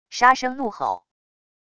杀声怒吼wav音频